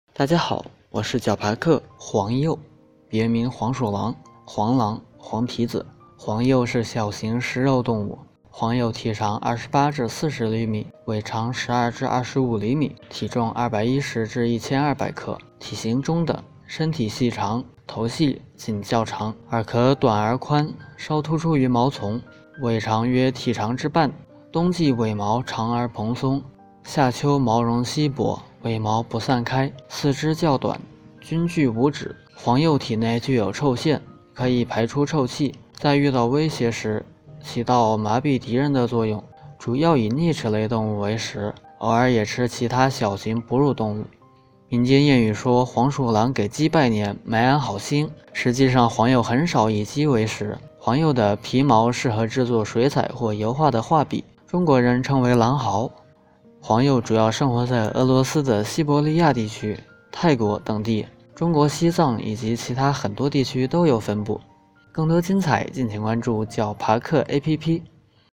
解说词: 黄鼬（拉丁学名：Mustela sibirica），别名黄鼠狼、黄狼、黄皮子。